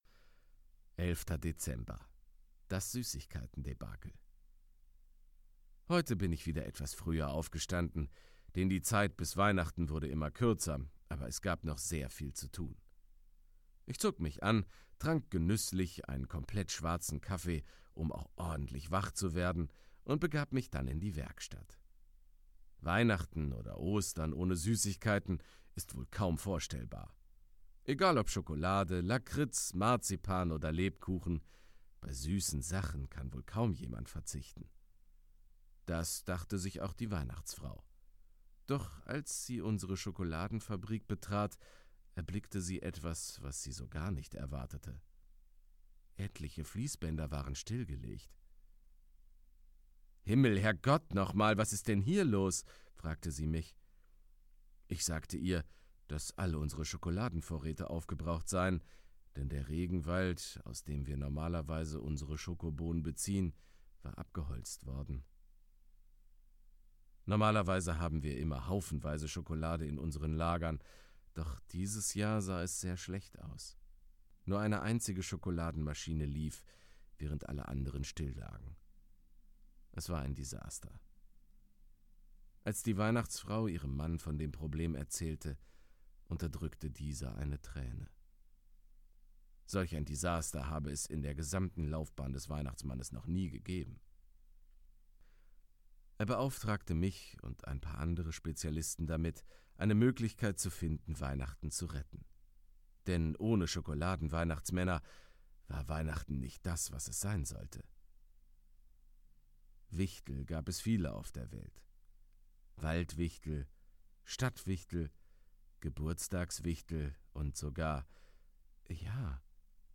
Sprecher*in